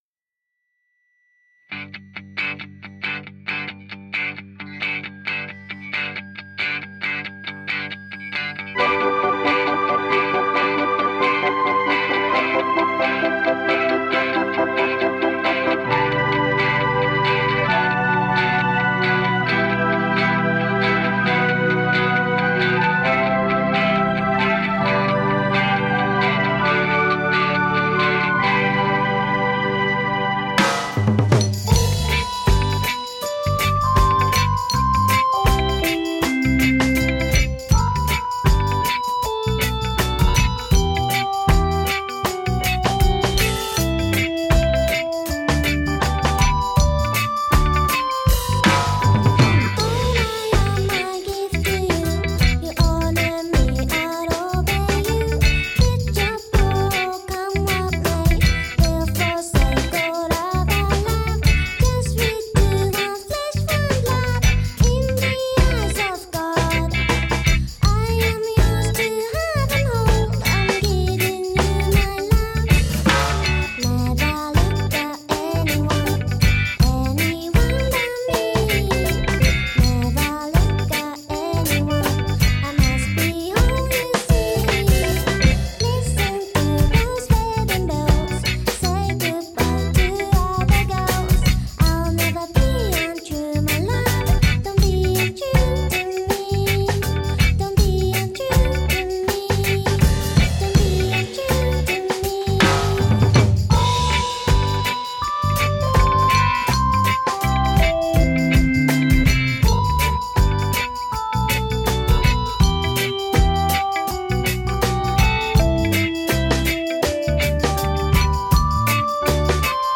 甘くて切ない胸キュンロックステディーです！
DRUMS＆PERCUSSION
TRUMPET＆TROMBONE＆FLUGELHORN ＆FLUTE